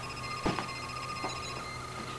med_tricorder.wav